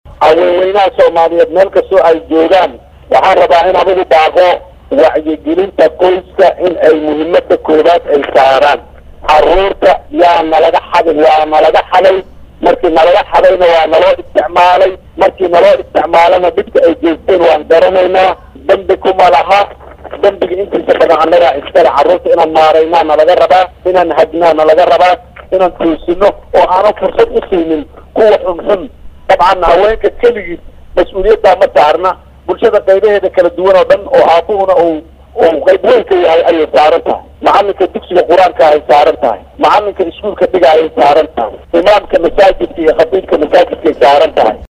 Muqdisho(INO)Munaasabad si heer sare ah loo soo agaasimay oo lagu xusayay Maalinta Haweenka ayaa ka soconeysa Xarunta Gaadiidka Booliska ee Magaalada Muqdisho.